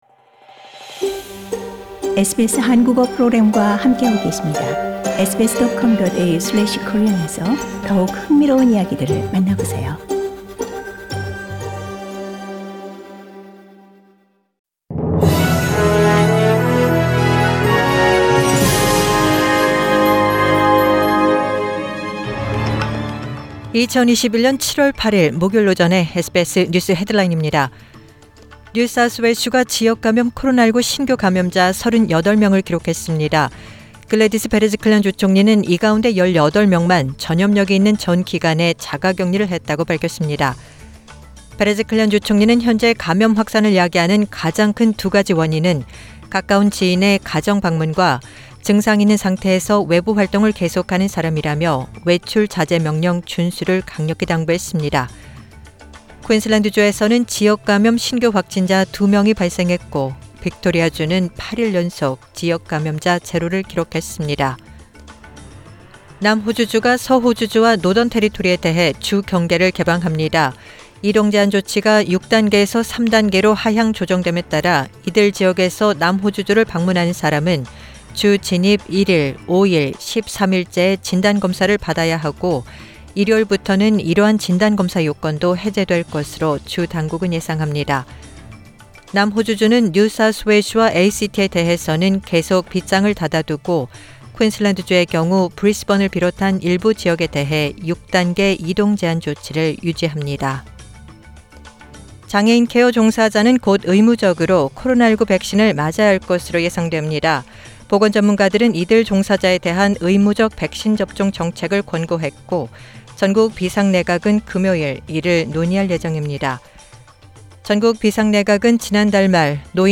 2021년 7월 8일 목요일 오전의 SBS 뉴스 헤드라인입니다.